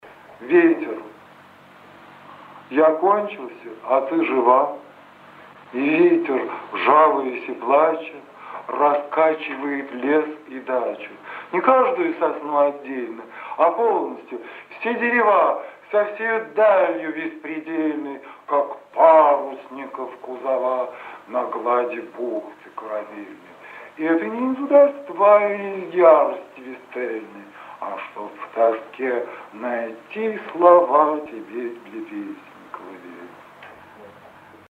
Читает автор.